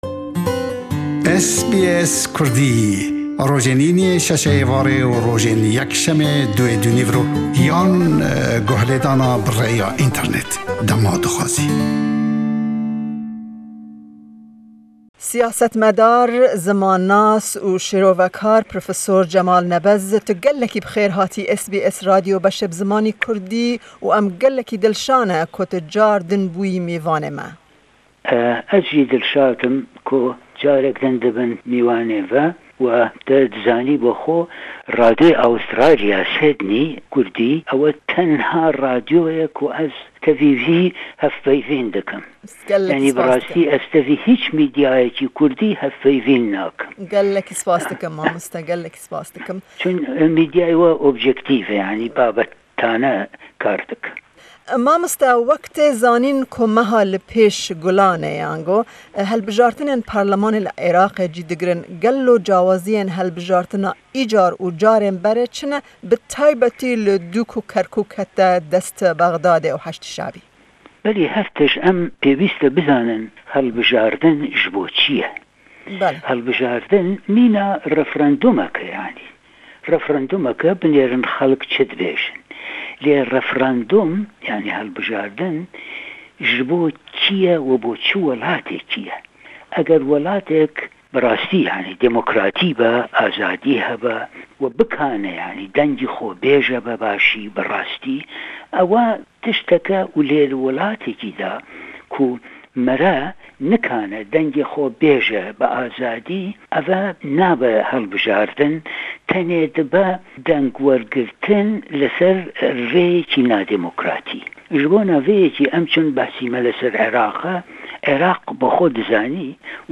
Di meha li pêsh de, Gulanê, dê helbijartinên parlemanî li Êraqê cî bigirin. Me derbarê wê mijarê hevpeyvînek bi analîst, nivîskar û siyasetmedar profisor Jemal Nebez pêk anî. Me li ser cûdabûna vê helbijartinê ji yên di chiye pirsî û gelo berjewendiya Kurdên bashûr di vê helbijantinê de hebin.